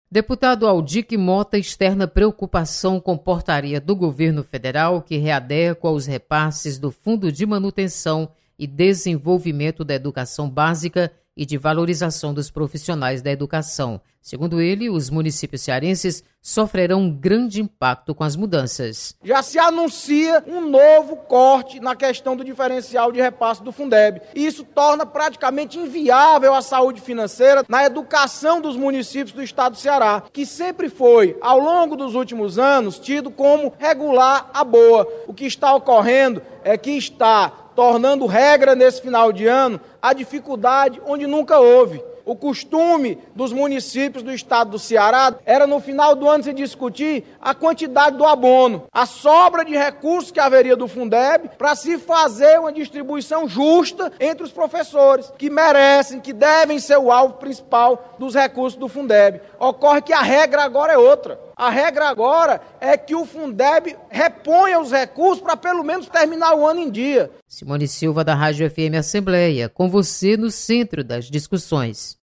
Você está aqui: Início Comunicação Rádio FM Assembleia Notícias Fundeb